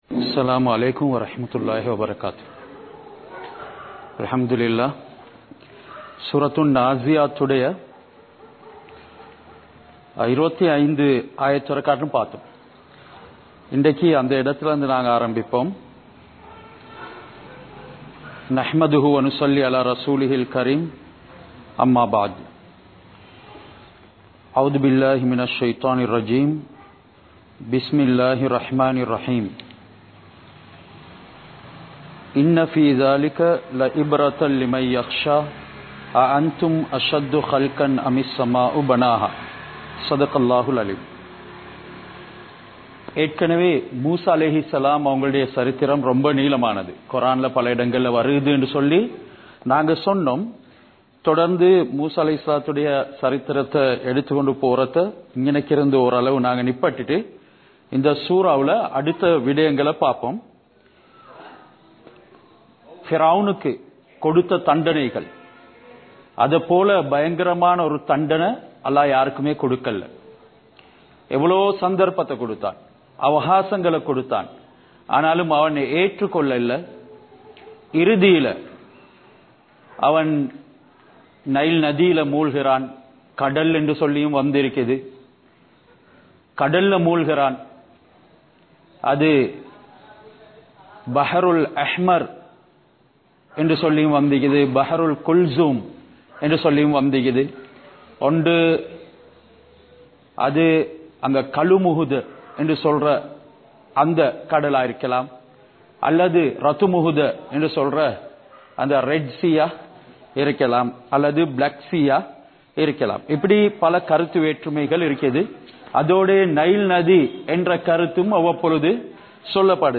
Surah An Naaziyath(Thafseer Versus 25-42) | Audio Bayans | All Ceylon Muslim Youth Community | Addalaichenai